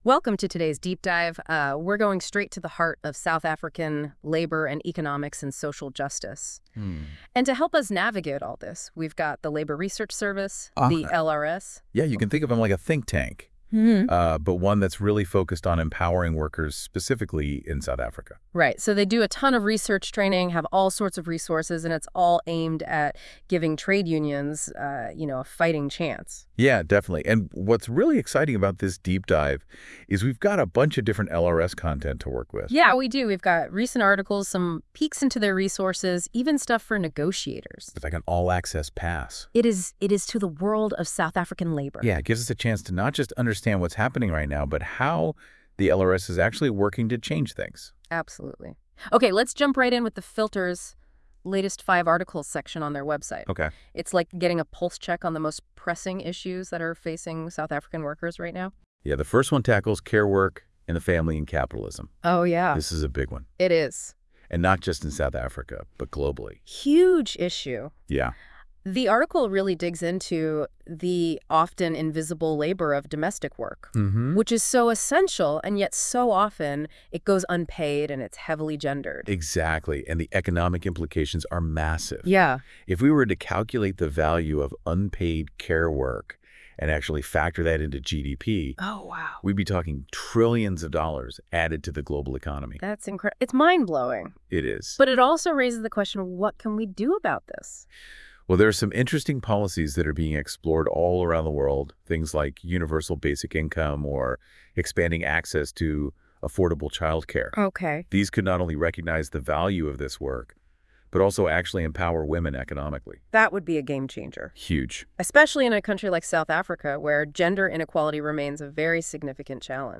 We also played around with Google’s ‘Notebook’ – which created a podcast based on the information solely from our website homepage.